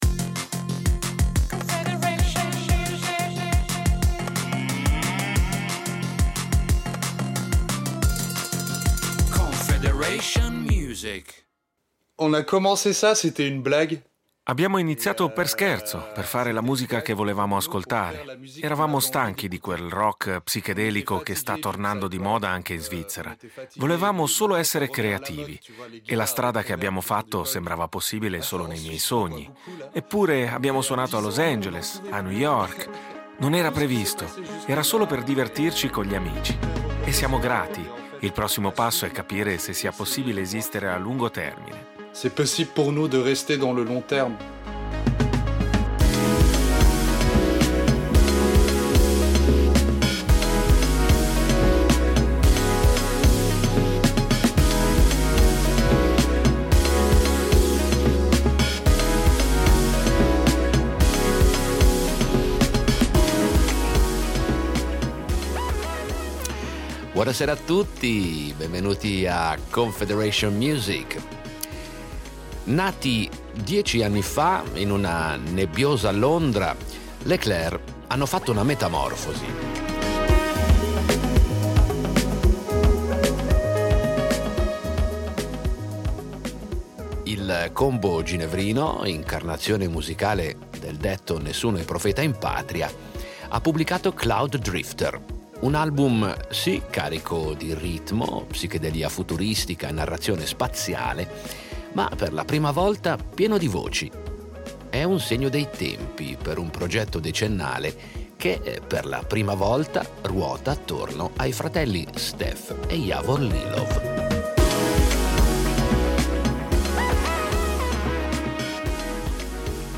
Musica pop L’Éclair
È una delizia sintetico-analogica, un pot-pourri stilistico che ti porta dentro scenari urbani e riviere stellari con la sensazione d’essere costantemente nella colonna sonora di un film di fantascienza. E con un denominatore comune: il groove.